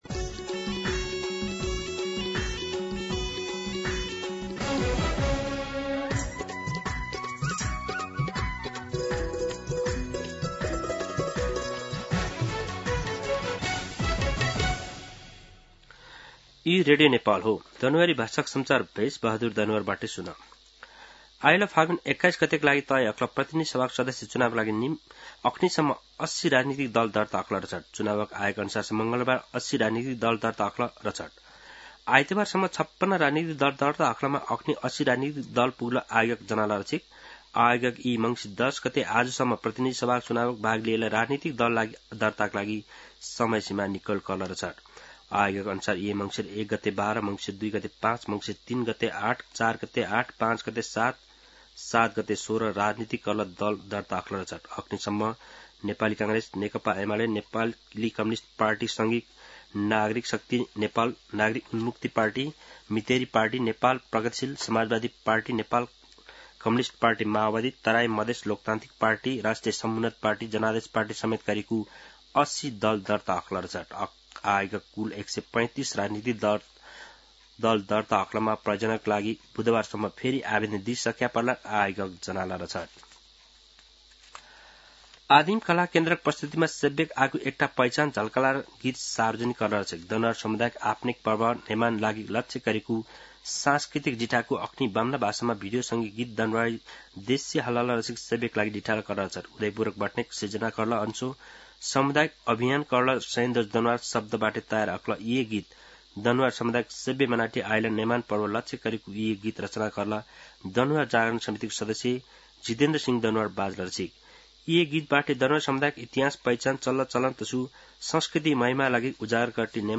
दनुवार भाषामा समाचार : १० मंसिर , २०८२
Danuwar-News-10.mp3